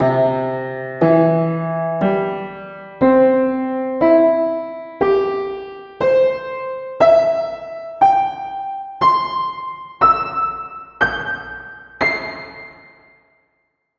🎵 Piano Arpeggio Sample - Generated by TestRenderPianoArpeggio
• FLAC sample loading (FreePats Upright Piano KW)
• Multi-velocity layers (soft/loud playing dynamics)
• Pitch-shifting (full 88-key range from sparse sample set)
• Loop support (continuous loops for sustained notes)
• Decent-quality reverb (Freeverb algorithm with hall-like acoustics)
piano_arpeggio.wav